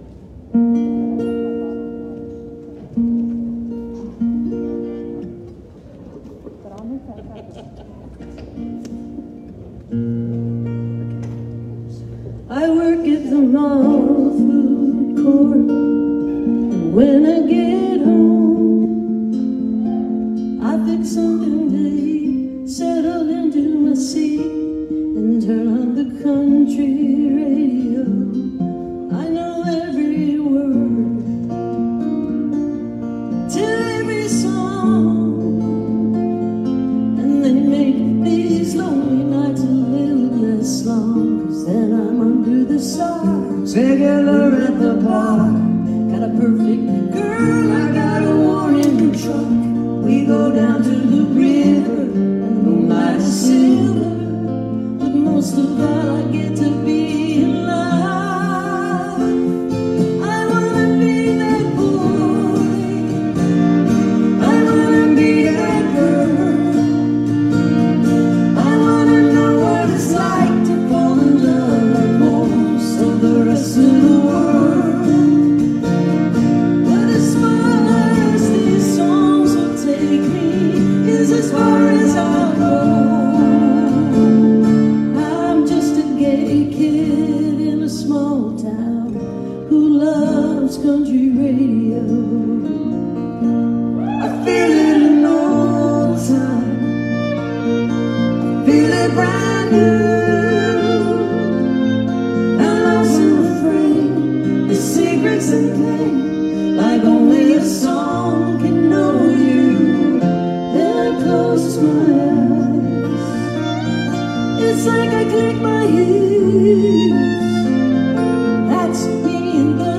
(captured from a facebook live stream)